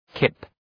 Προφορά
{kıp}